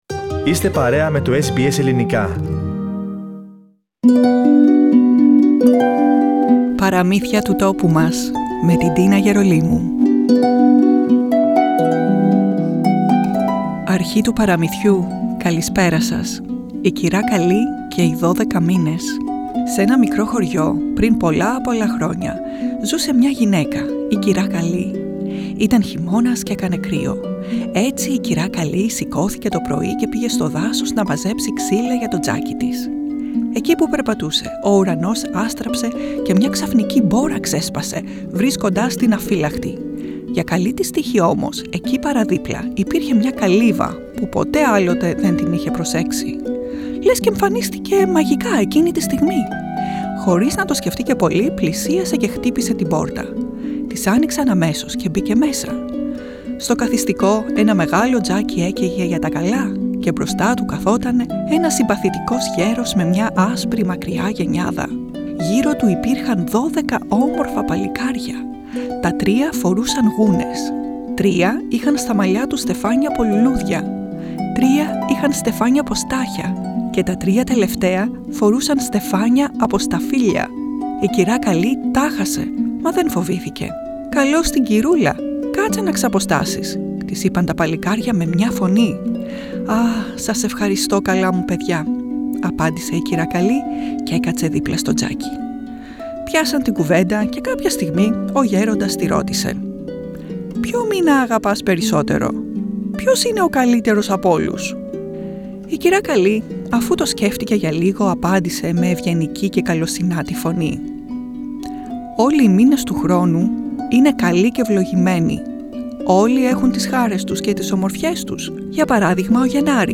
Διδάσκει την αξία του να μπορείς να βλέπεις και να εκφράζεις τα θετικά σε κάθε κατάσταση. Αφήγηση